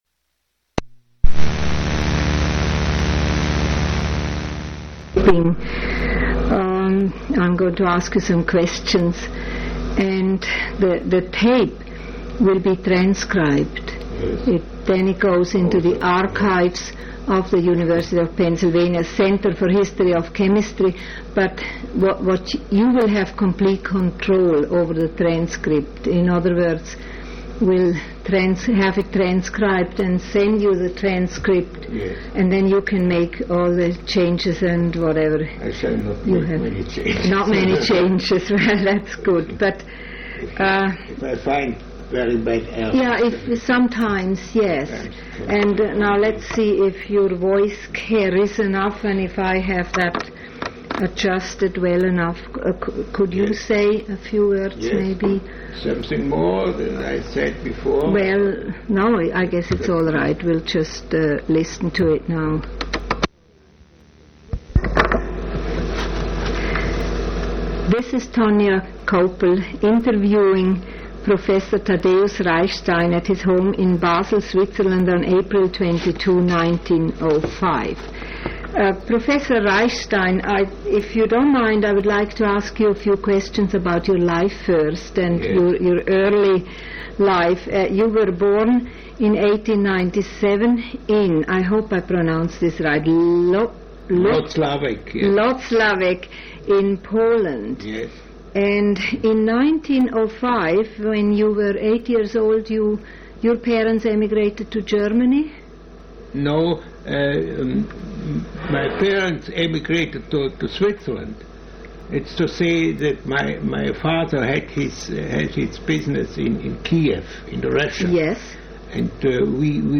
Place of interview Switzerland--Basel
Genre Oral histories